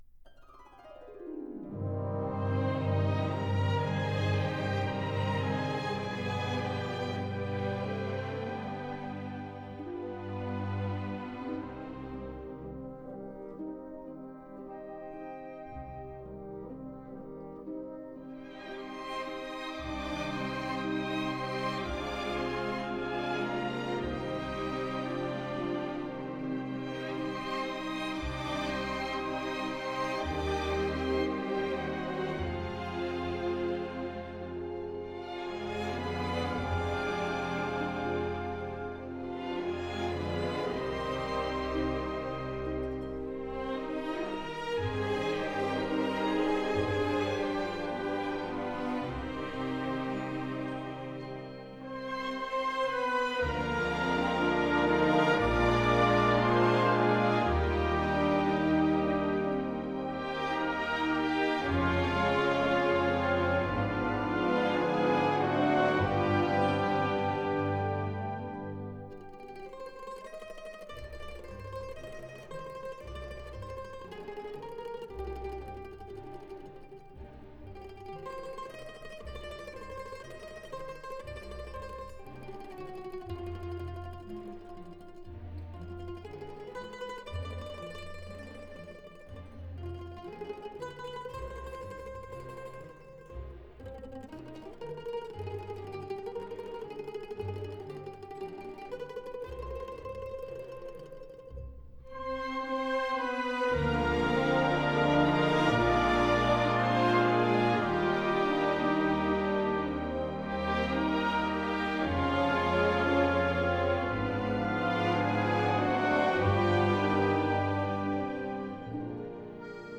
听他指挥的音乐会感到气势磅礴，震撼无比。
以二十位数字录音录制的